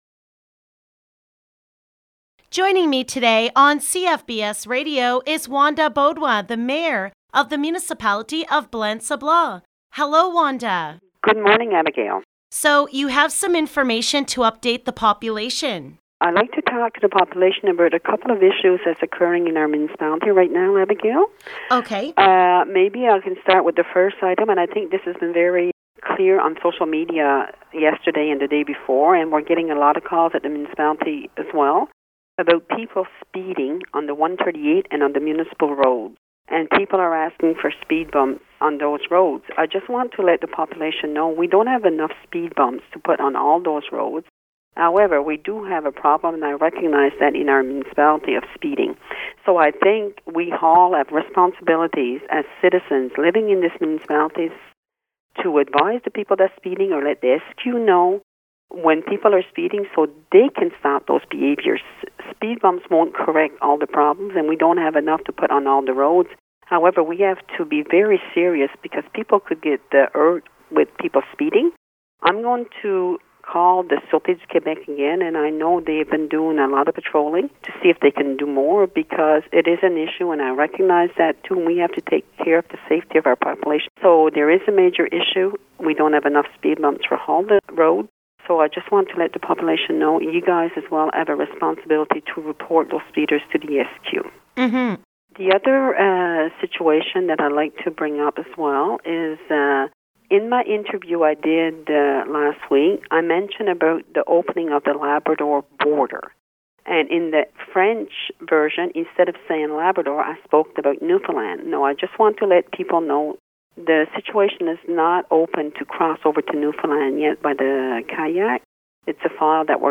An update from the Mayor of the Municipality of Blanc-Sablon, Wanda Beaudoin, regarding: